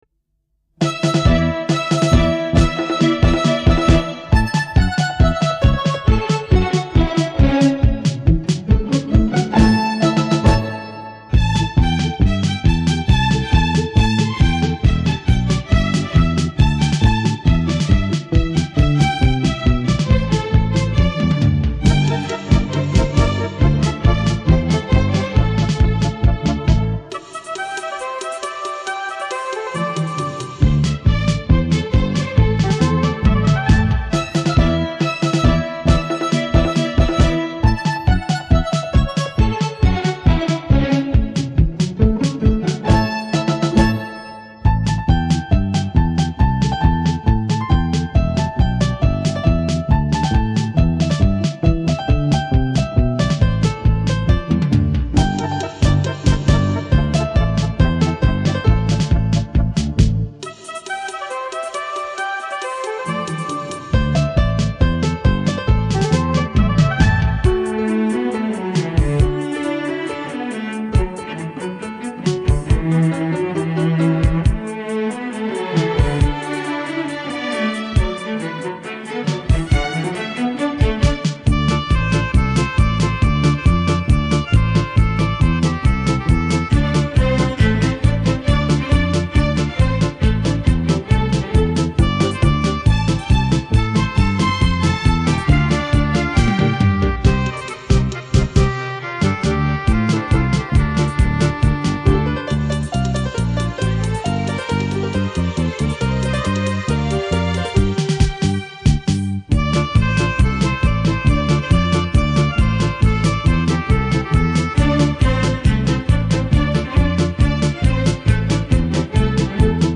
卡带转WAV
（快曲）